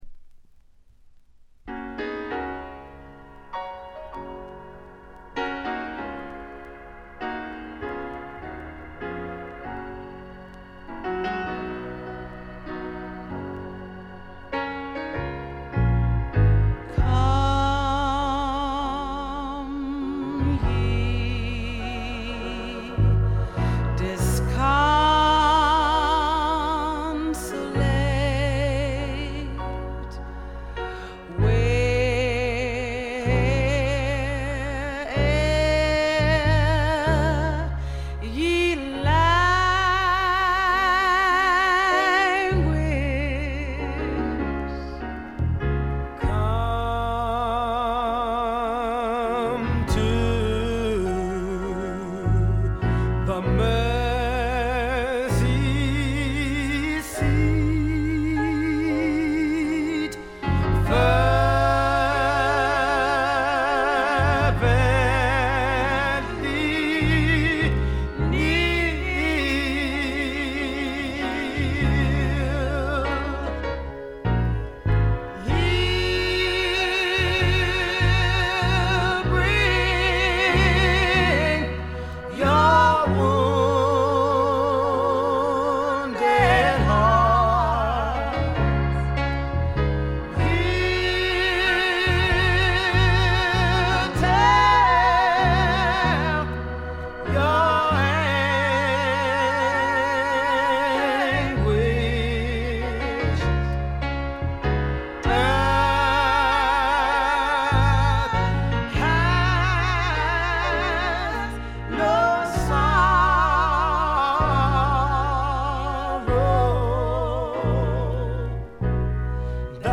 ごくわずかなノイズ感のみ。
頂点を極めた二人の沁みる名唱の連続でからだが持ちませんね。
試聴曲は現品からの取り込み音源です。